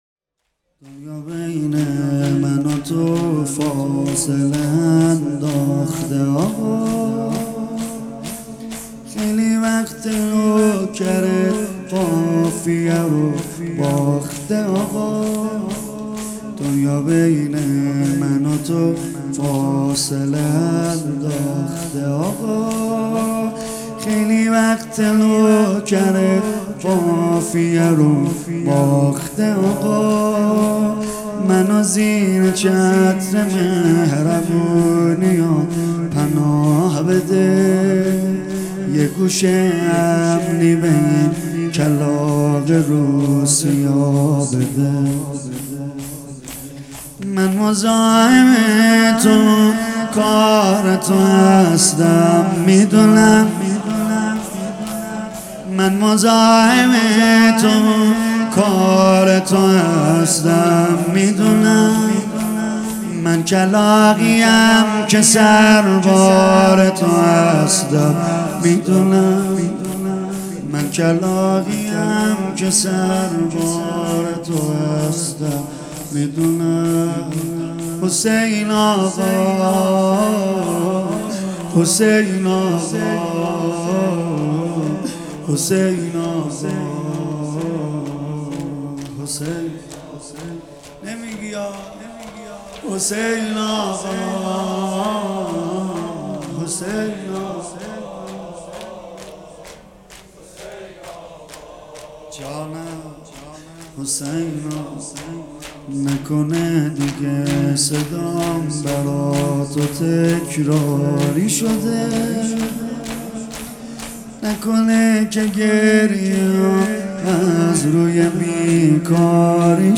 خیمه گاه - حاج سید رضا نریمانی - 20شهریور-شور_بین من و تو فاصله انداخته آقا